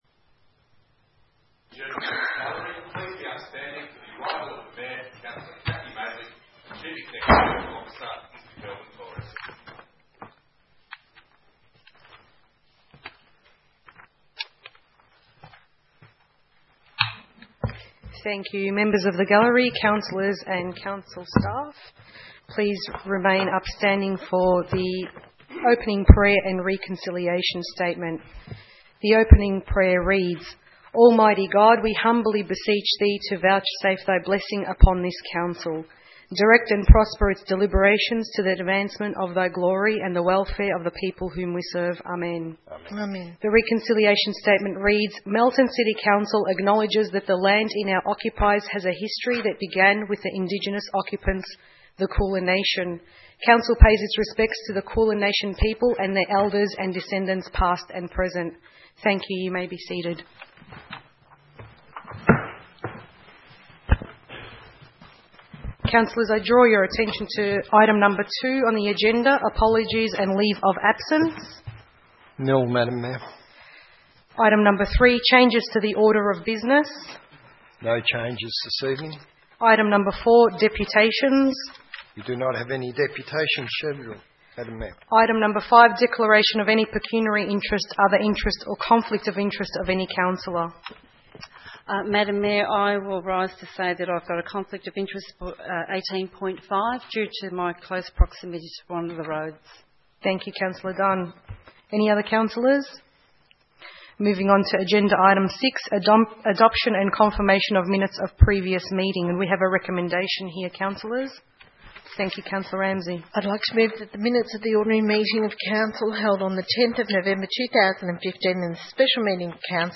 15 December 2015 - Ordinary Council Meeting